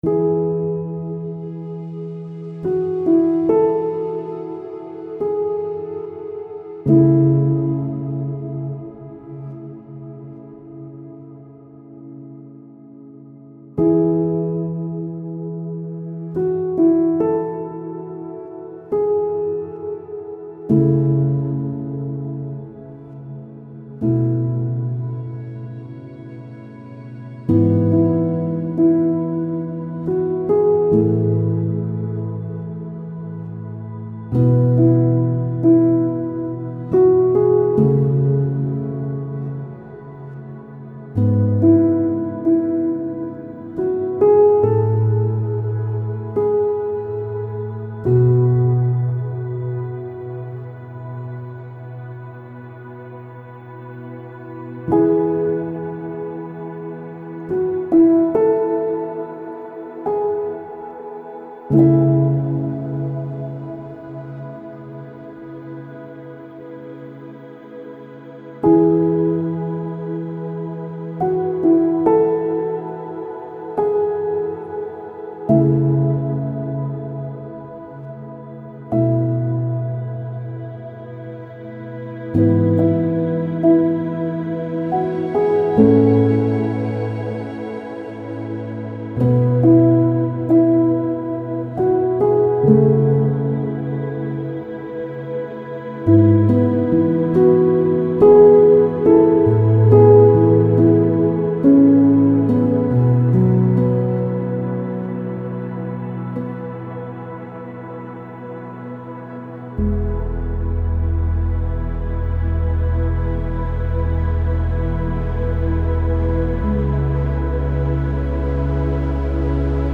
پیانو امبینت